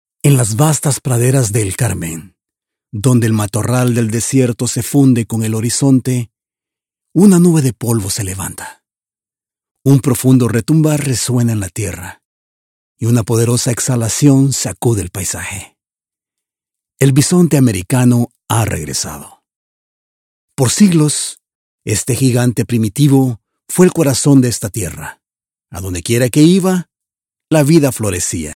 Bilingual clear voice, dynamic, positive, emotive, promotional, corporative, friendly.
Spanish - Documentary
My PRO STUDIO is equipped with SOURCE CONNECT STANDARD (available at an extra rate), PRO TOOLS, AKG 414 B/ULS mic, and AVID MBOX STUDIO.